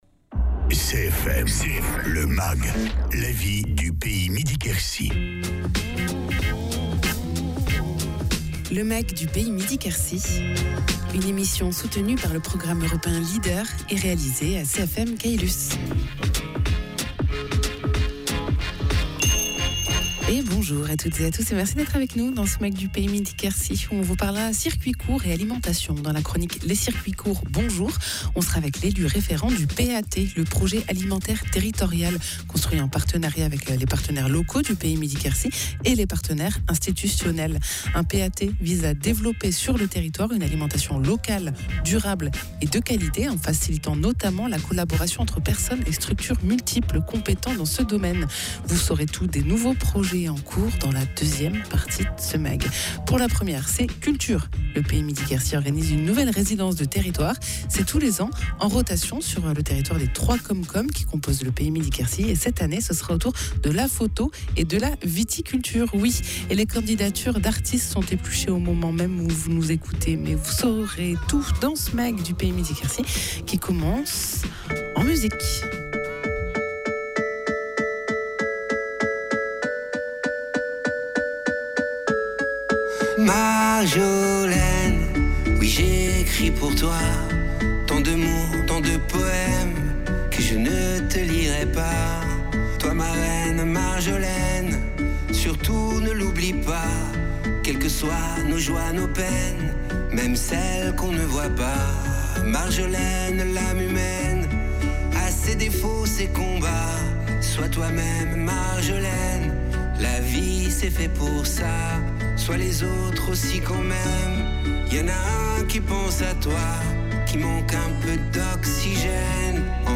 Gérard Craïs, élu référent du PAT du Pays Midi-Quercy